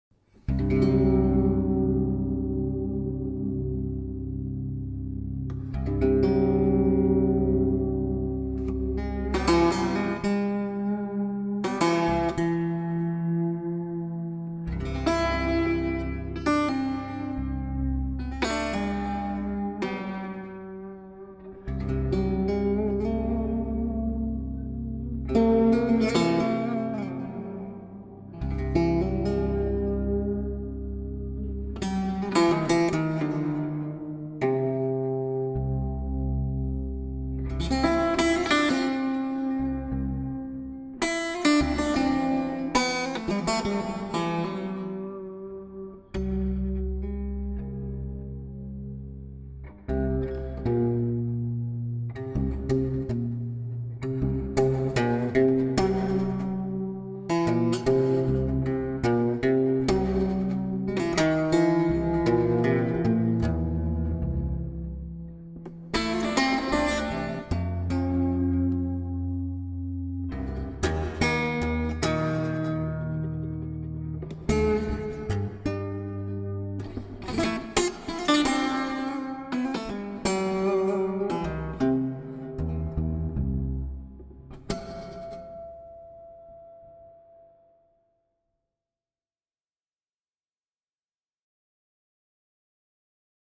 Solo semi-acoustic guitar